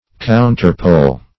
Search Result for " counterpole" : The Collaborative International Dictionary of English v.0.48: Counterpole \Coun"ter*pole`\ (-p?l`), n. The exact opposite.